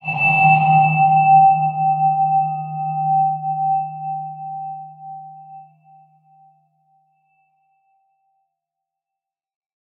X_BasicBells-D#1-pp.wav